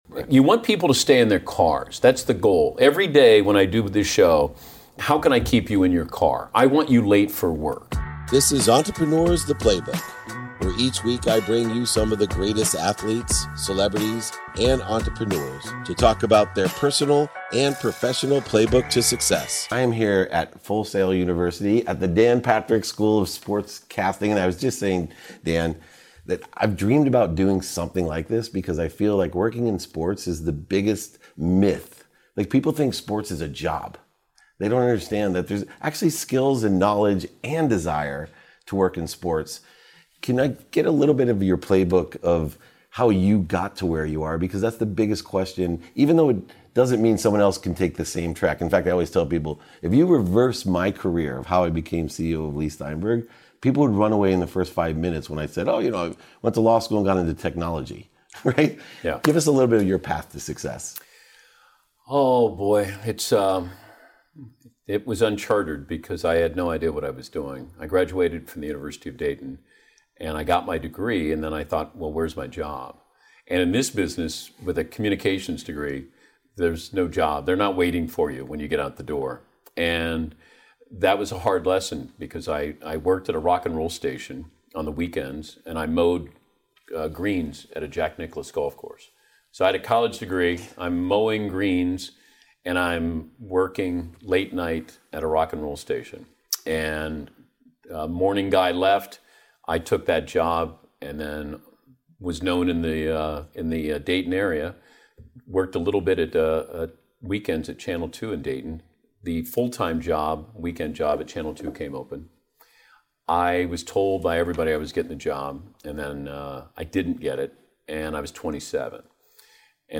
Today’s episode is from a conversation I had back in 2018 with sports broadcasting legend Dan Patrick, host of the Dan Patrick Show. We talked about his winding career path to ESPN, how he dealt with rejection as a young man, and how he teamed up with Full Sail University to create a modern Sports Broadcasting program to provide the next generation of sportscasters with the right tools to compete in the media industry.